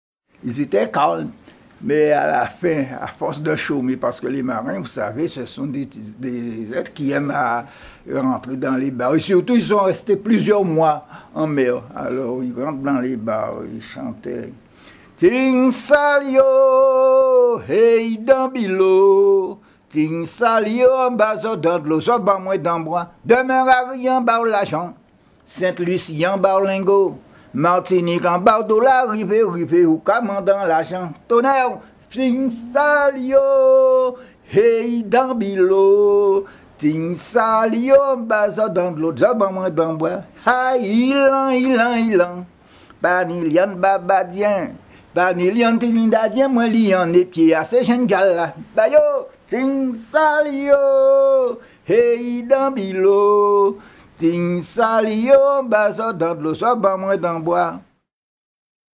chant de travail de la fête des marins, enregistré sur l'île Marie-Galante le 25 avril 2009
chants brefs